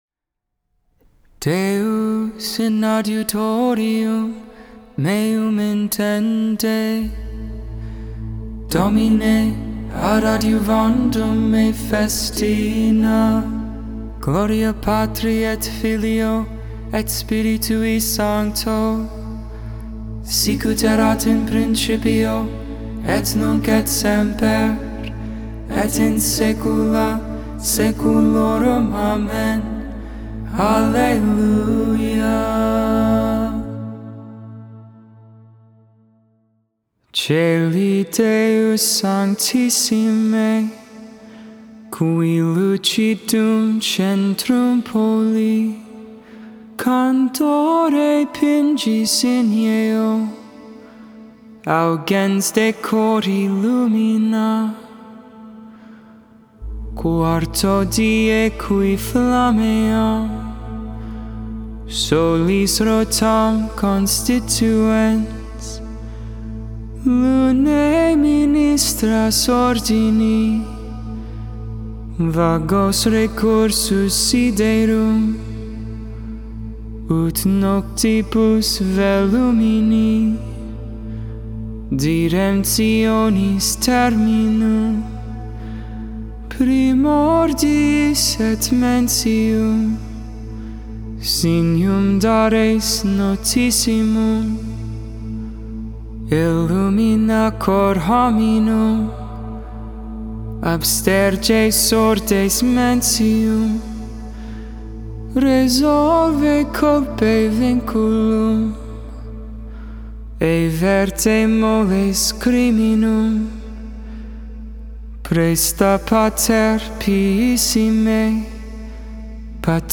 12.15.21 Vespers, Wednesday Evening Prayer